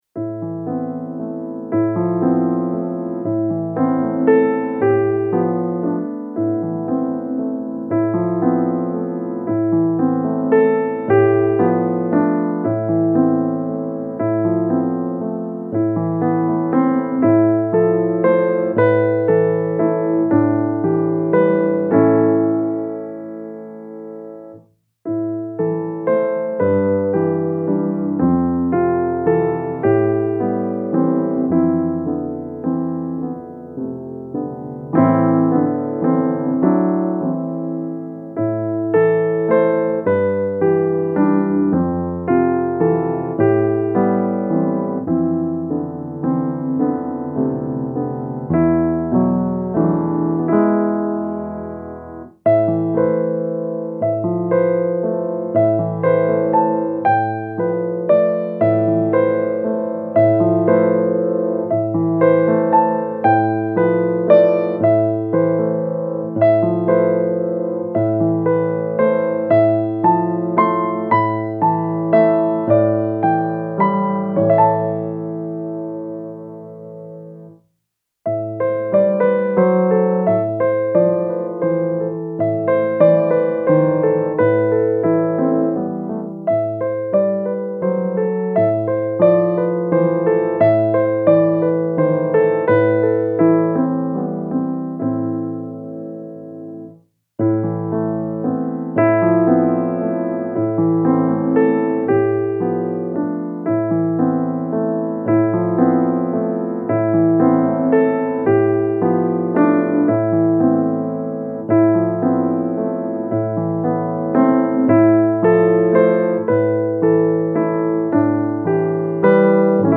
pianiste de jazz